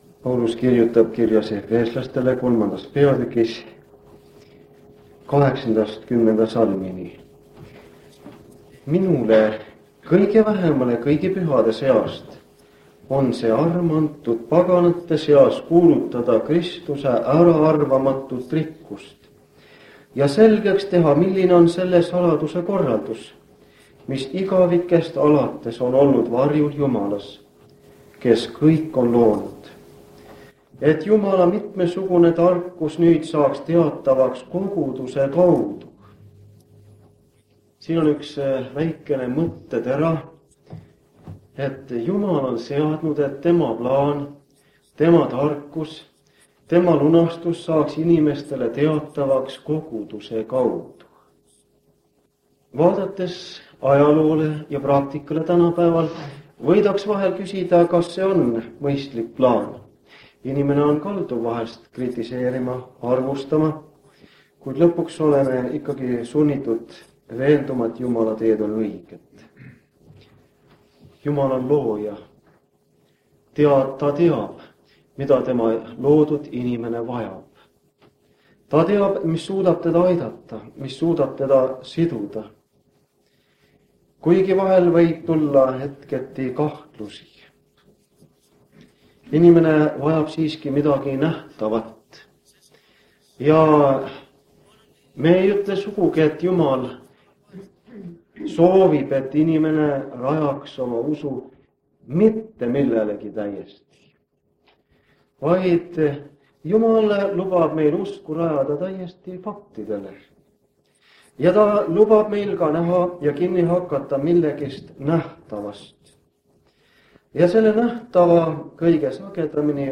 1983 aasta jutlus vanalt lintmaki lindlilt.
Jutlused